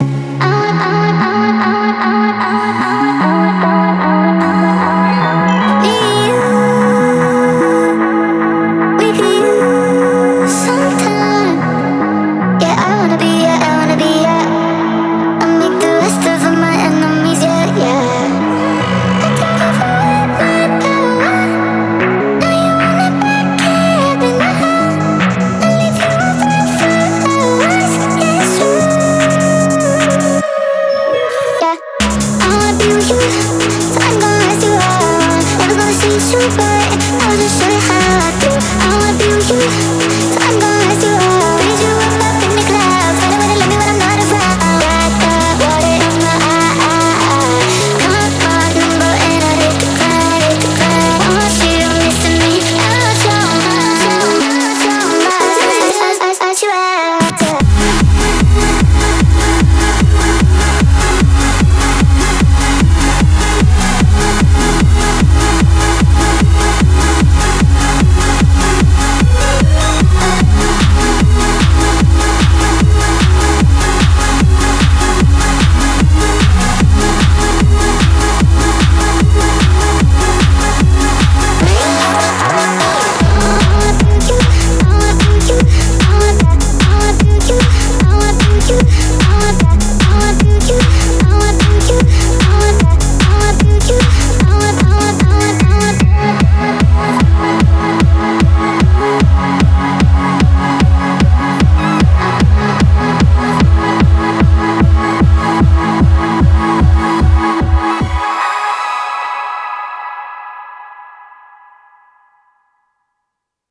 BPM150
Audio QualityMusic Cut
An awesome banger of a tune.